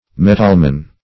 metalman - definition of metalman - synonyms, pronunciation, spelling from Free Dictionary Search Result for " metalman" : The Collaborative International Dictionary of English v.0.48: Metalman \Met"al*man\, n.; pl.